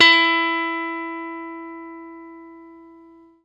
FENDRPLUCKAK.wav